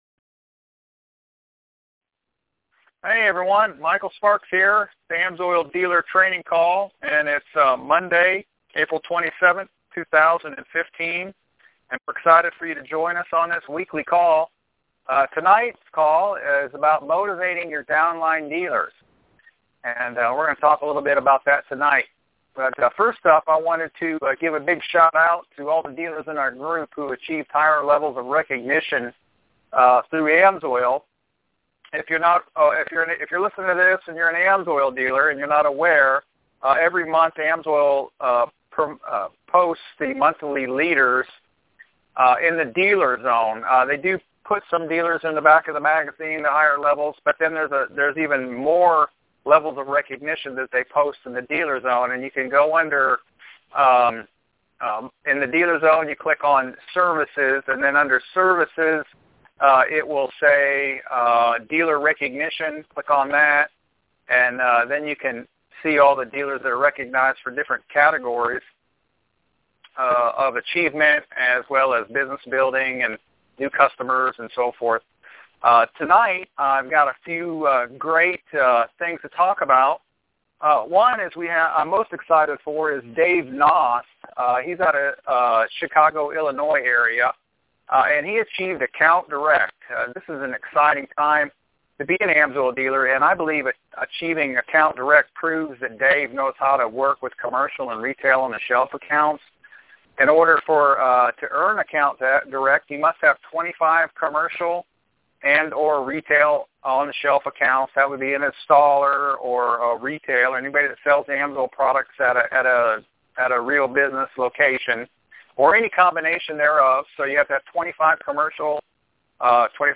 Training Call